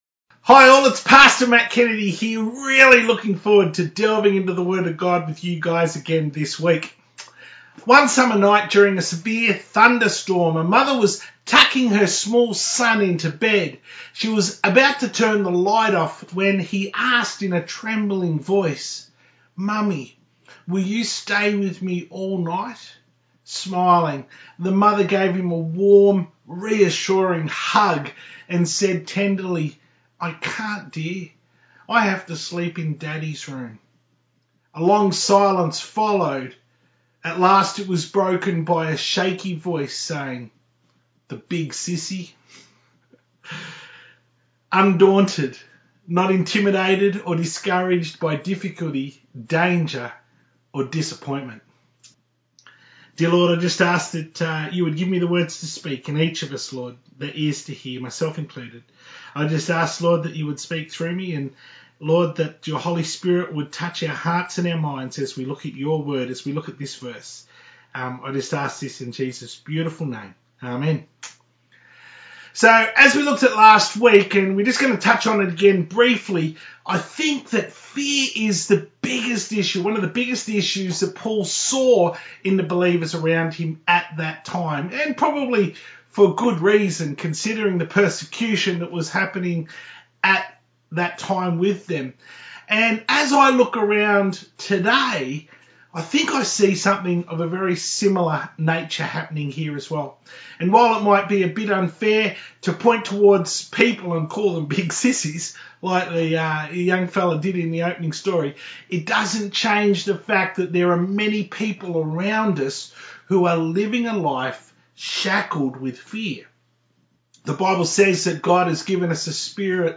To view the Full Service from 8th November 2020 on YouTube, click here.